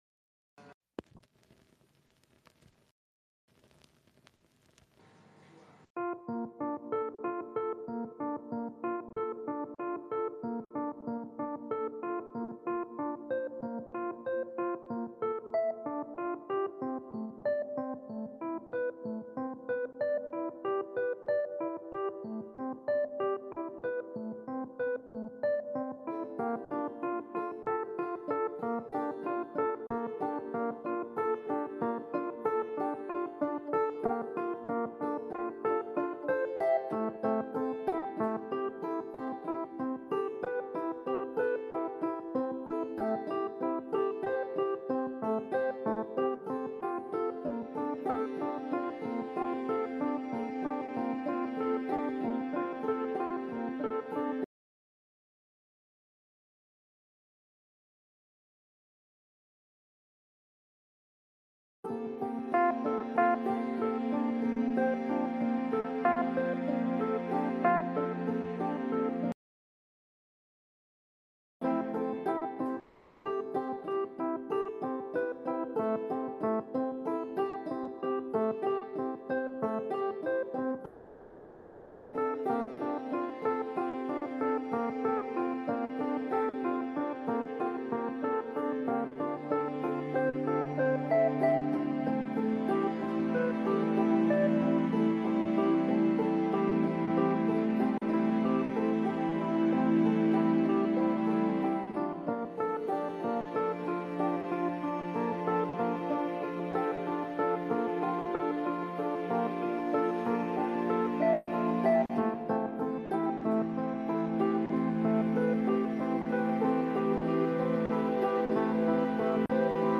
Hey there, thank you for joining our twitter space for this program.